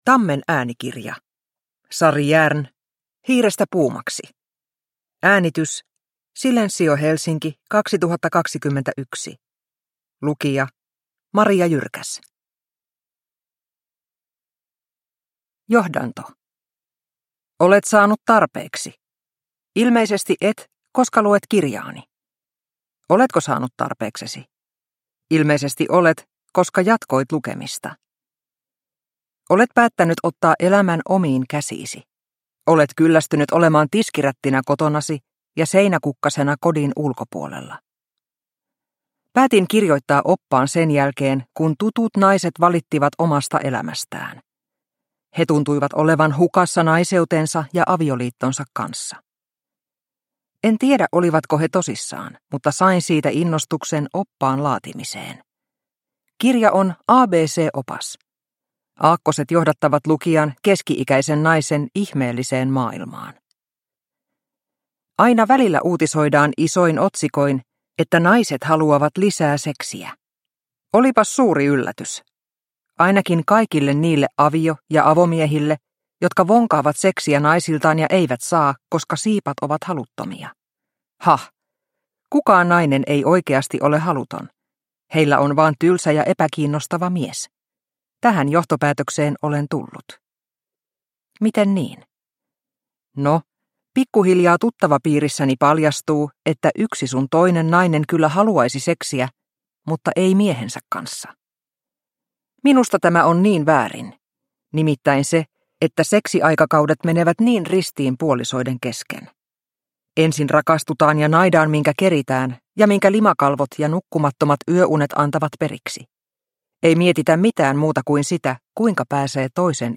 Hiirestä puumaksi – Ljudbok – Laddas ner